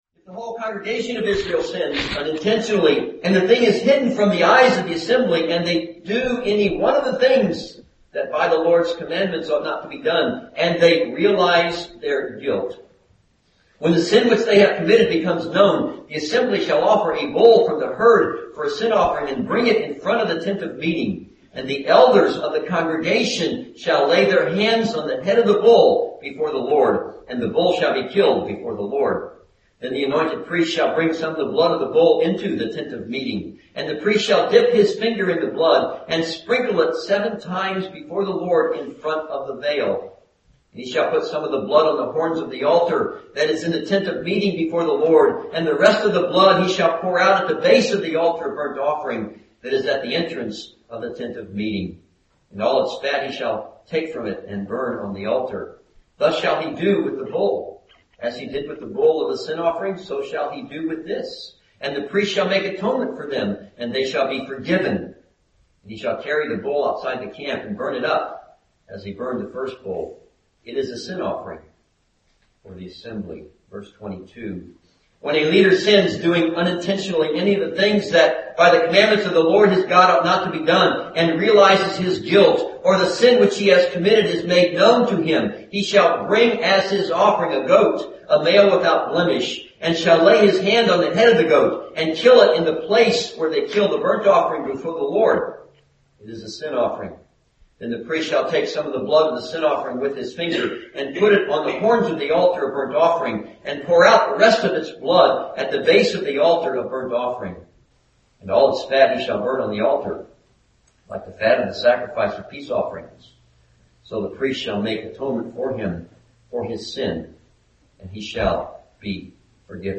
This sermon is based on Leviticus 4:1-5:13 and Leviticus 6:24-30.